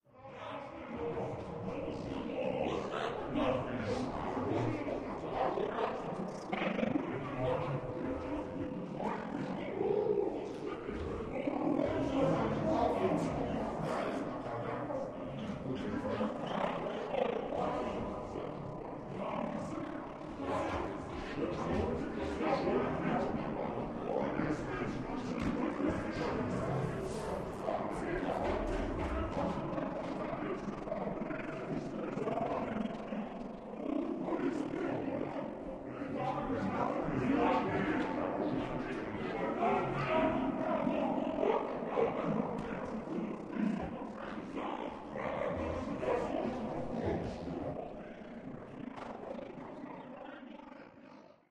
Aliens Talking Ambience Several